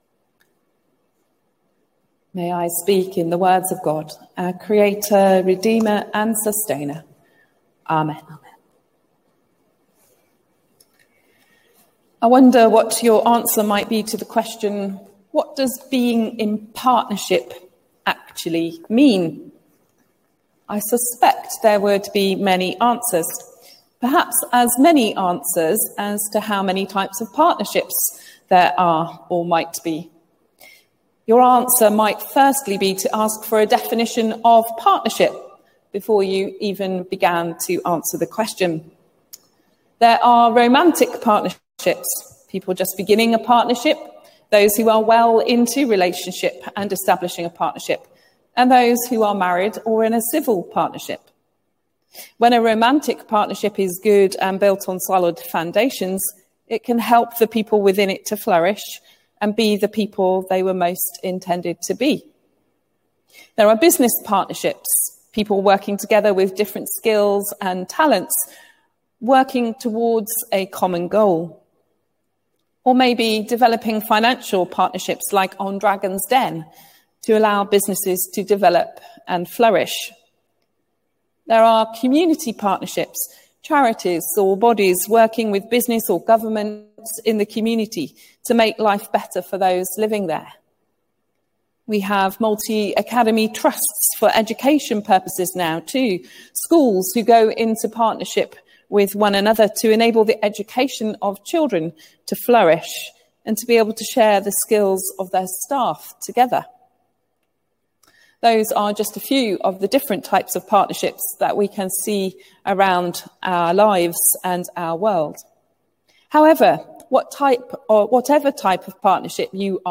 Sermon: Partnership and choice | St Paul + St Stephen Gloucester
thursday-eucharist-14th-january_vJofK5eE_DQ4c.mp3